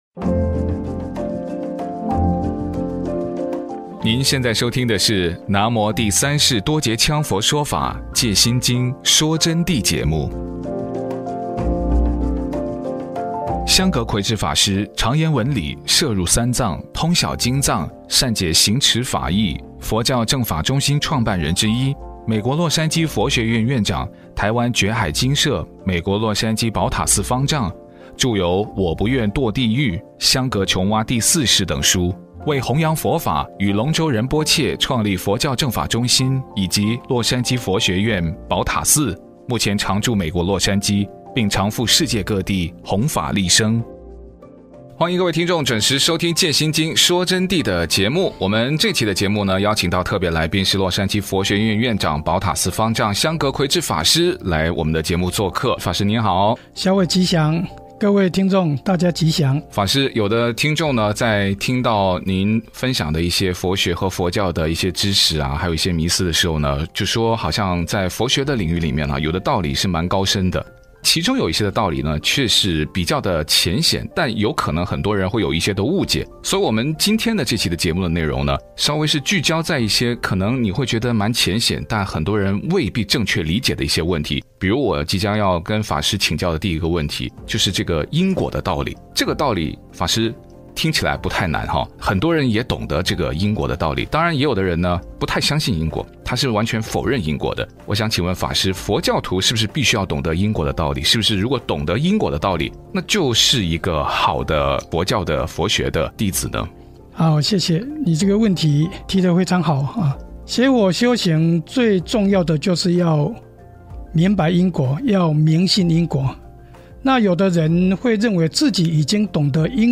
▲佛弟子访谈（二十六）懂得因果与明信因果的区别？因果业报的原理和业果的特质是什么？